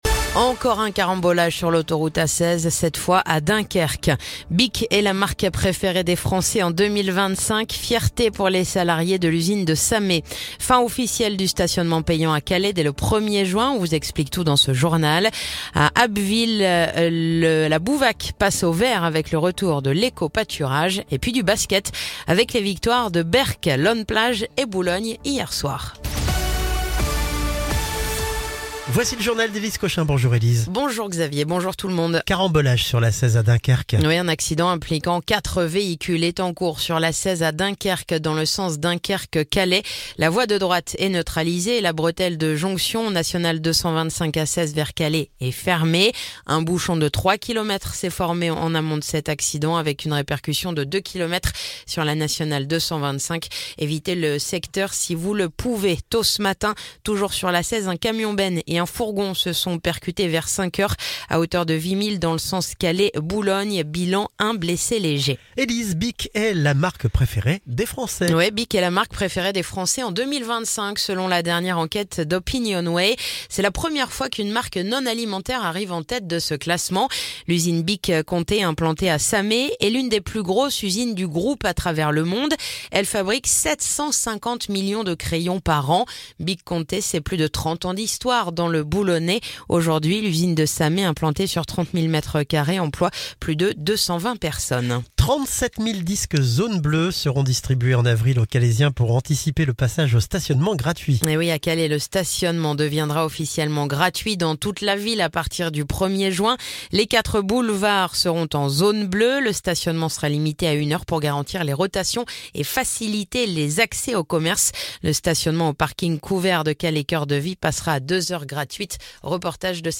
Le journal du mercredi 26 mars